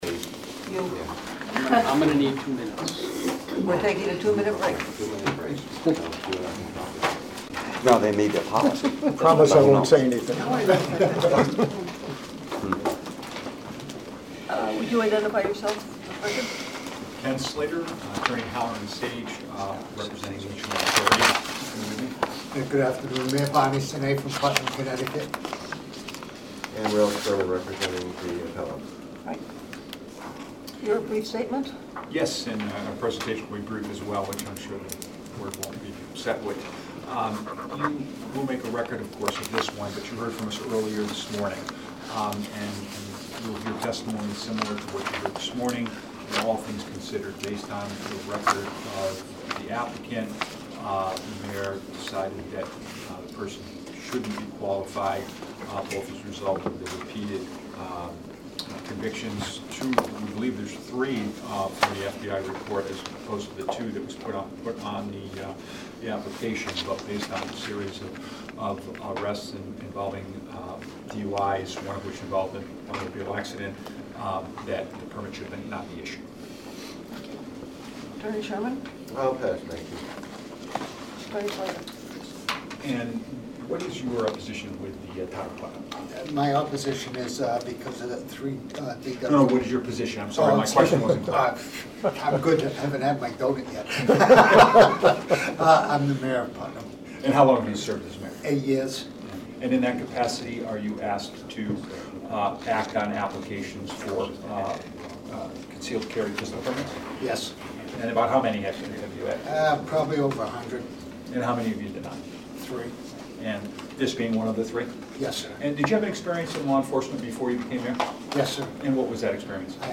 Meeting of the Board of Firearms Permit Examiners